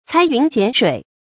裁云剪水 注音： ㄘㄞˊ ㄧㄨㄣˊ ㄐㄧㄢˇ ㄕㄨㄟˇ 讀音讀法： 意思解釋： 裁：裁剪。